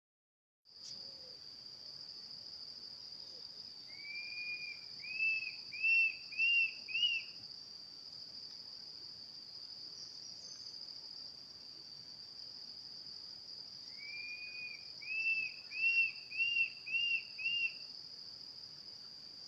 Tufted Antshrike (Mackenziaena severa)
Location or protected area: Parque Provincial Cruce Caballero
Condition: Wild
Certainty: Observed, Recorded vocal
Batara-Copeton.mp3